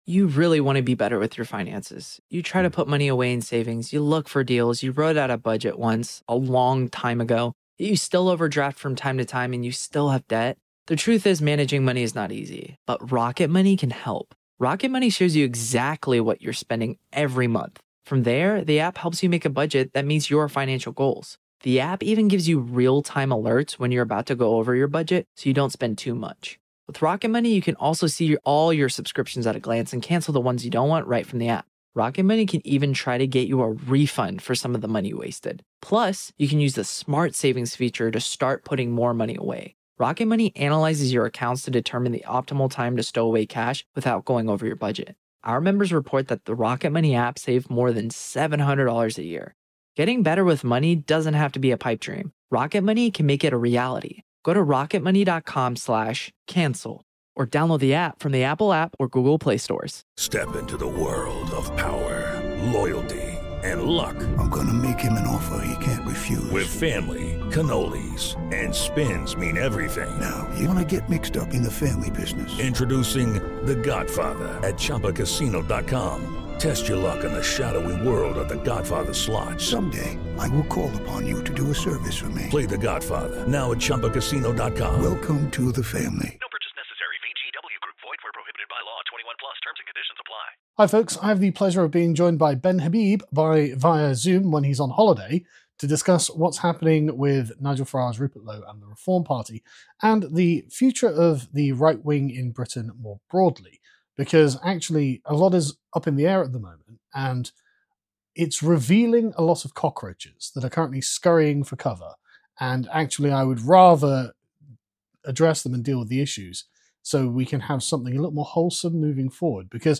What Comes Next? | Interview with Ben Habib